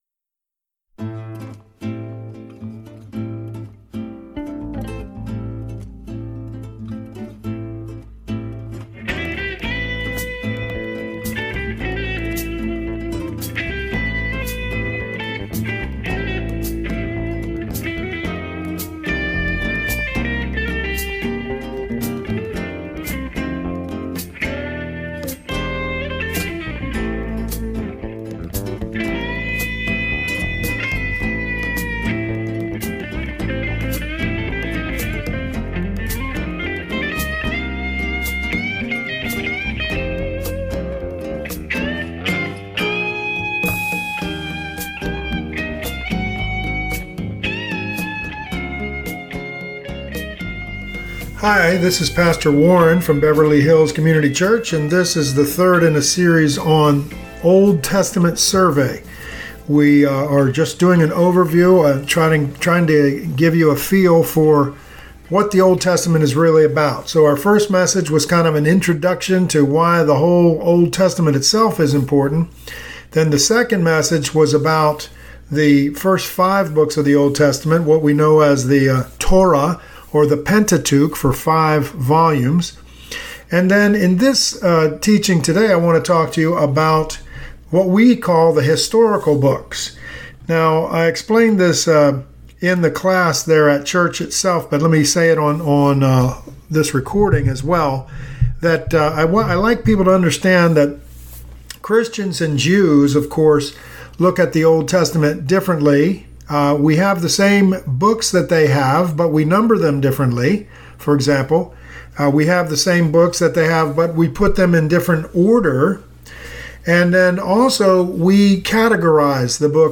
Christians divide the Old or First Testament, the Hebrew Bible or "Tanakh" into four sections. This teaching looks at the third, the historical books.